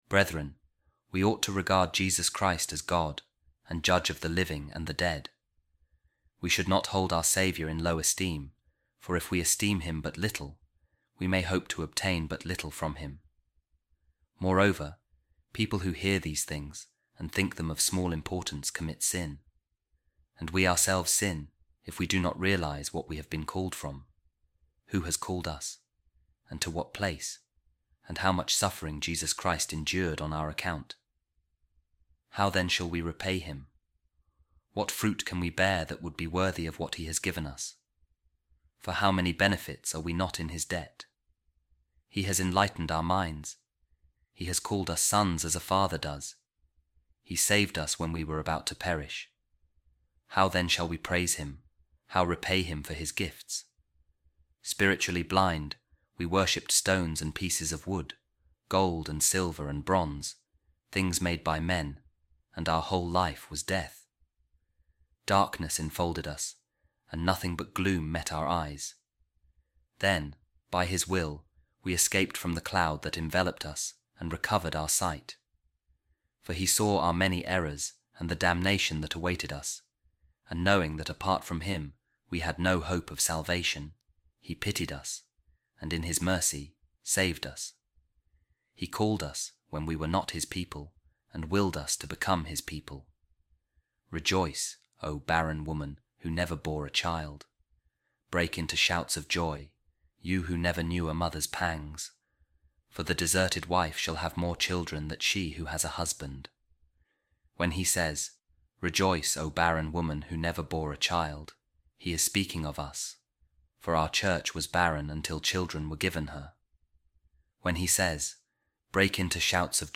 A Reading From A Homily Of A Second-Century Author | Jesus Christ Willed To Save Those Who Were About To Perish